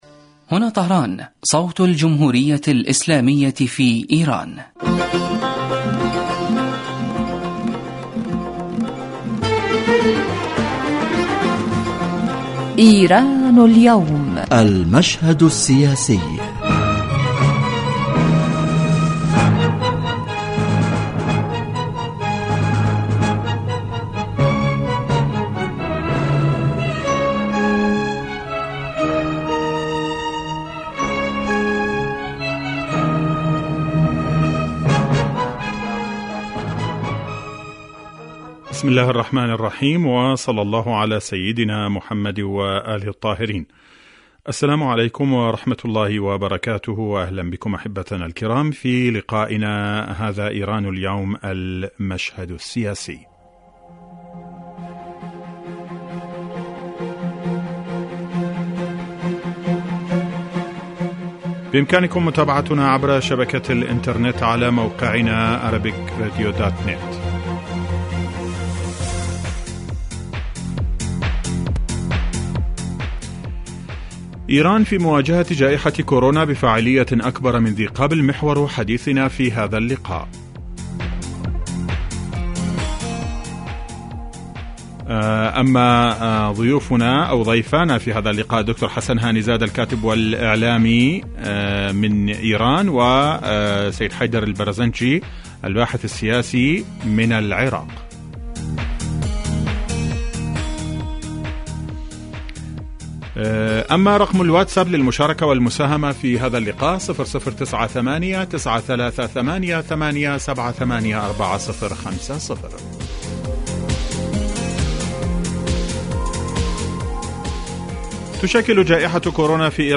يتناول هذا البرنامج كما هو واضح من تسميته آخر القضايا والأحداث الإيرانية ويختص كل أسبوع بموضوع من أهم موضوعات الساعة في ايران وتأثيره على الساحة الإقليمية ويتطرق إليه ضيف البرنامج في الاستوديو كما يطرح نفس الموضوع للمناقشة وتبادل النظر على خبير آخر يتم استقباله على الهاتف